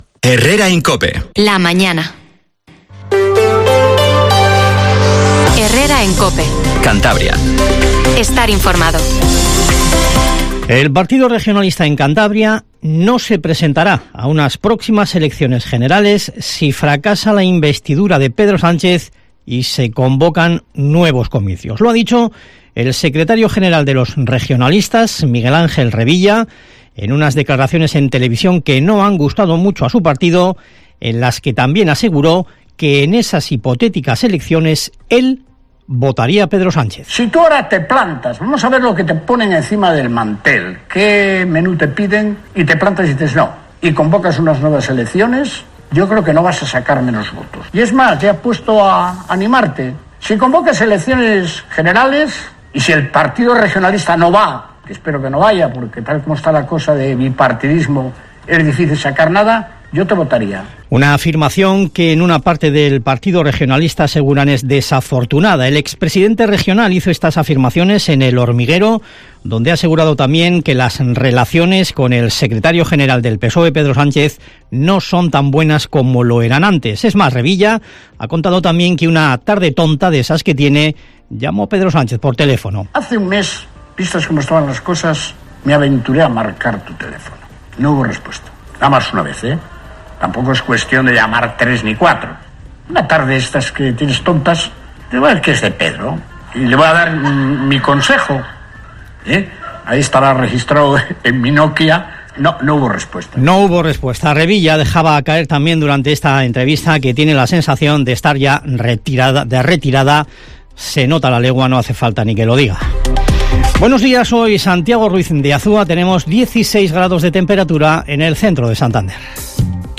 Informativo HERRERA en COPE CANTABRIA 07:50